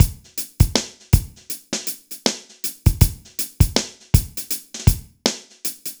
Index of /90_sSampleCDs/AKAI S6000 CD-ROM - Volume 4/Others-Loop/BPM_80_Others1